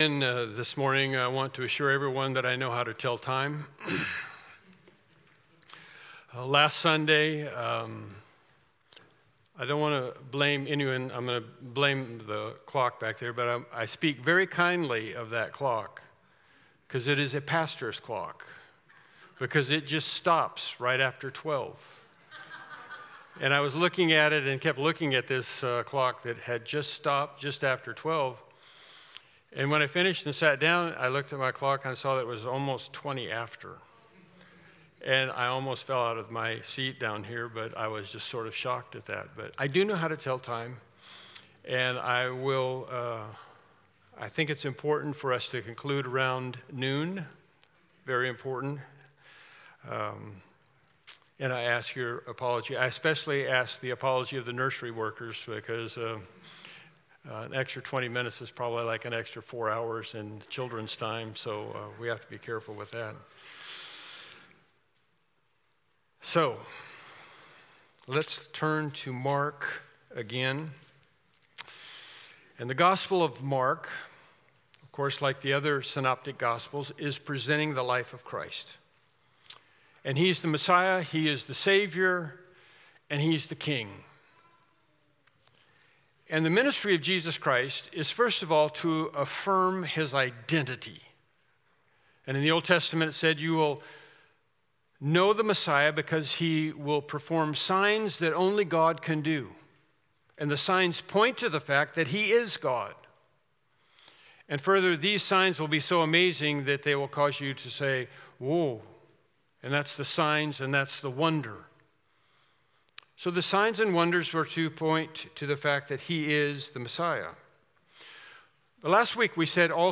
Mark 1:16-20 Service Type: Morning Worship Service « Lesson 12